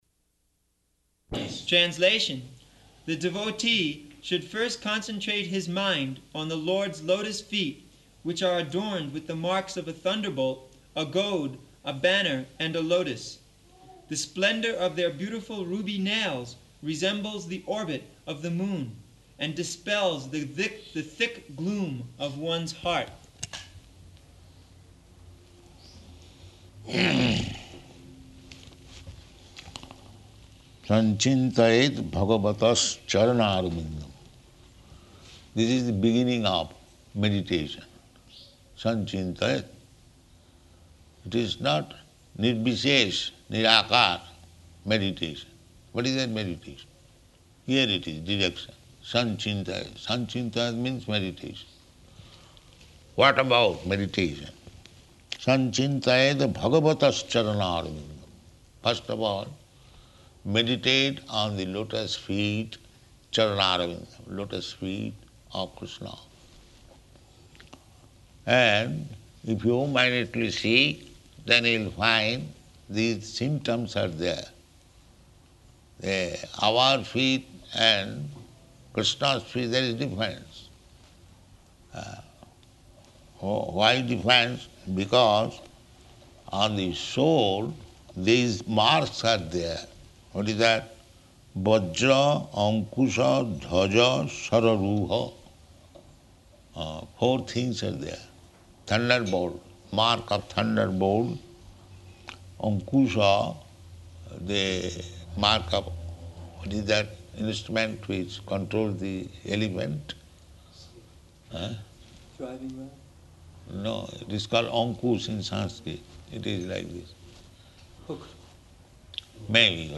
Location: Nairobi